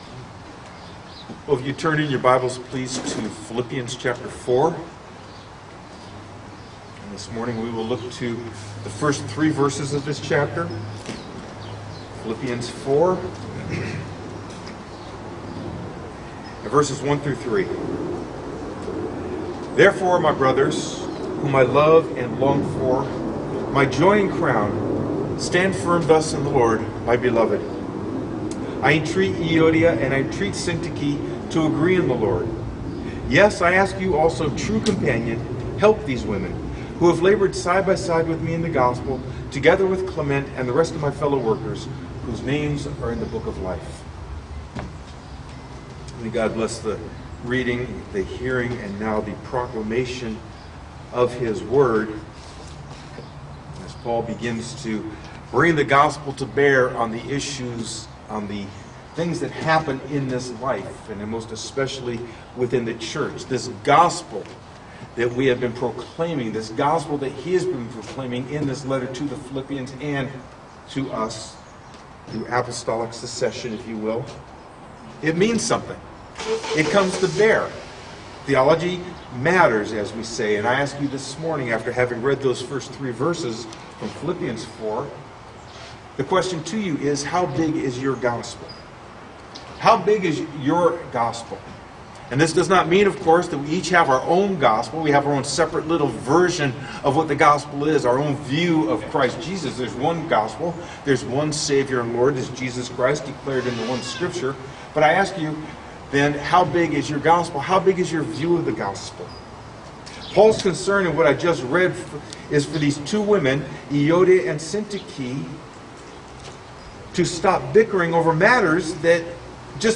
Sermon: How Big Is Your Gospel?